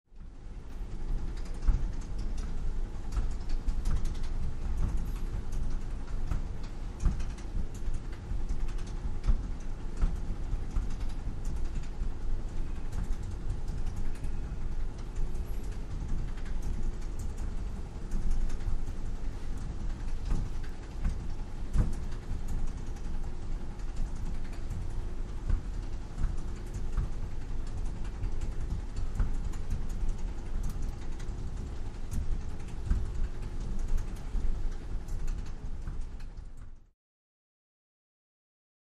Luggage Conveyor Belt | Sneak On The Lot
Baggage Claim, Motor Of Luggage Conveyor Belt, No Walla, Close Point of View.